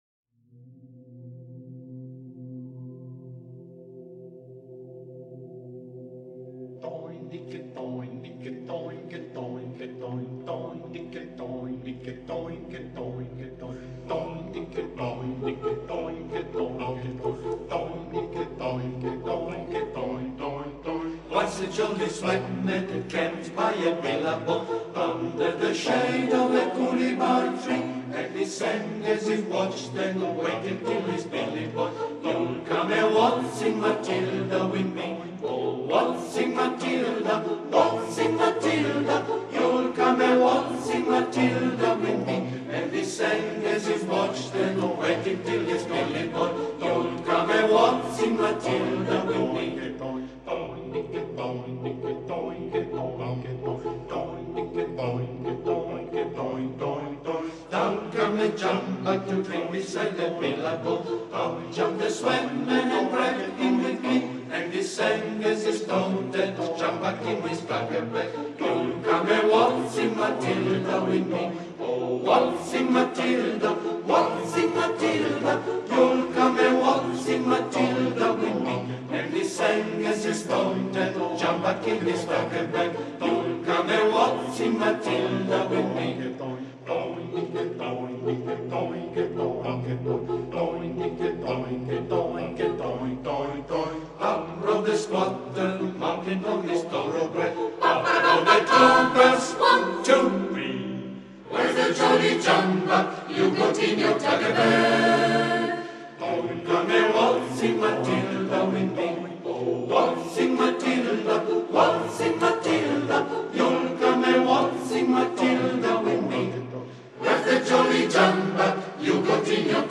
Esecutore: I *coristi torinesi